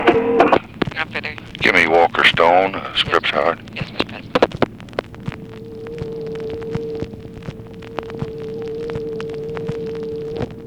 Conversation with TELEPHONE OPERATOR
Secret White House Tapes | Lyndon B. Johnson Presidency